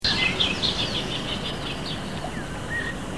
En besværlig sanger
Sangen, der er meget særpræget, er samtidig rimeligt monoton.
/ 75 kb): En kort, meget kraftig strofe.